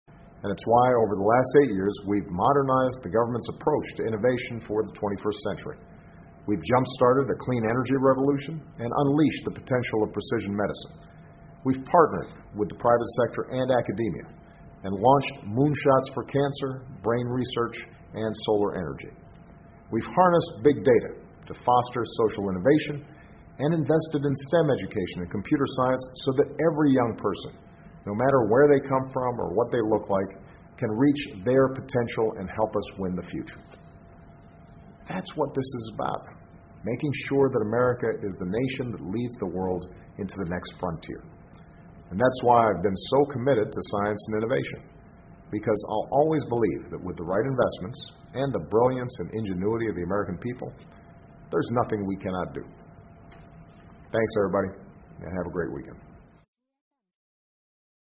奥巴马每周电视讲话：总统呼吁确保美国引领世界开拓新边疆（03） 听力文件下载—在线英语听力室